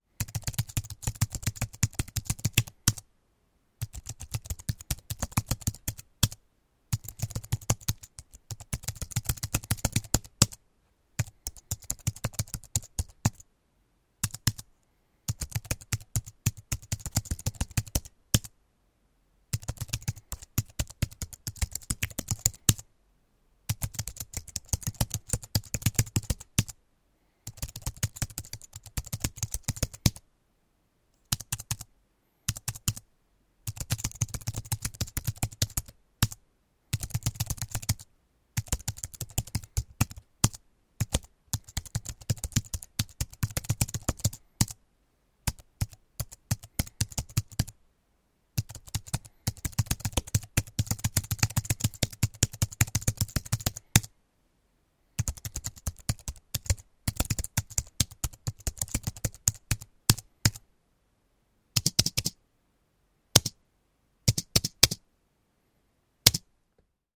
Звук программиста за работой у компьютера